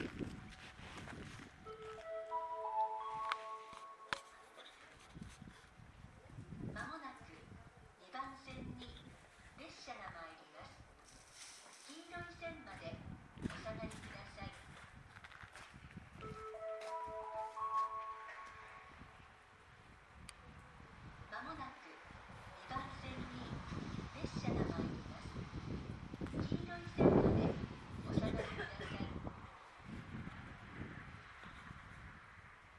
接近放送普通　青森行き接近放送です。